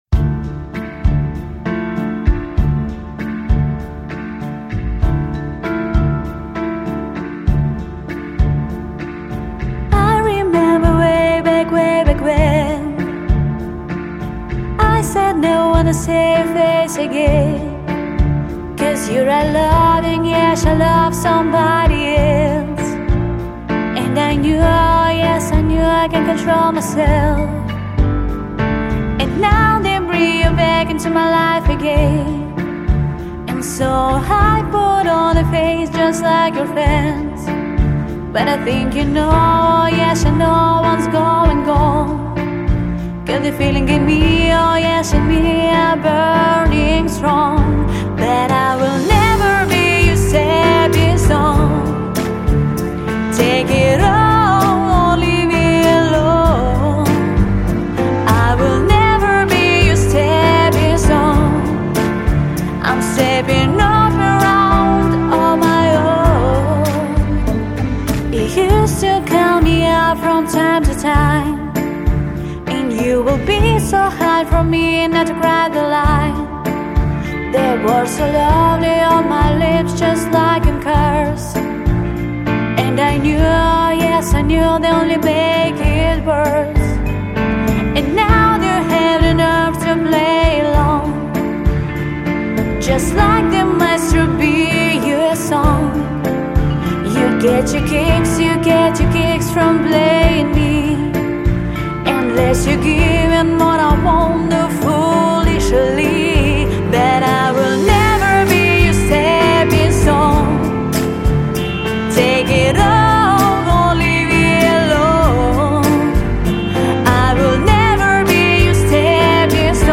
Певческий голос
Меццо-сопрано